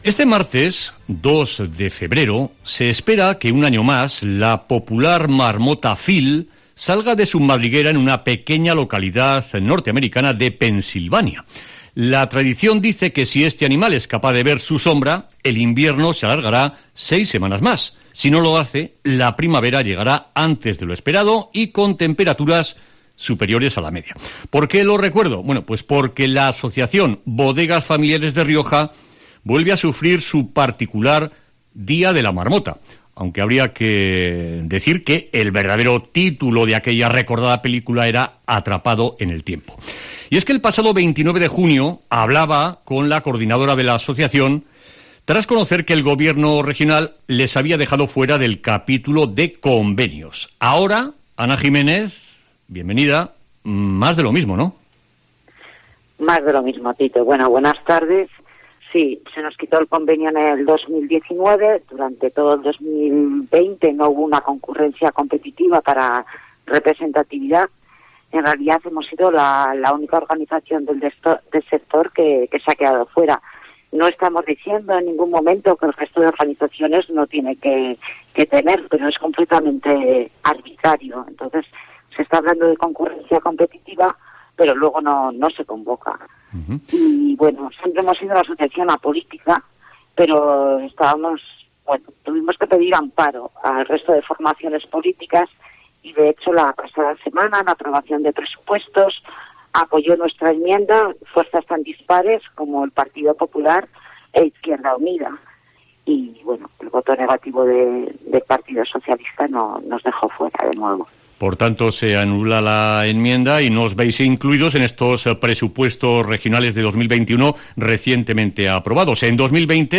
Entrevista en COPE Rioja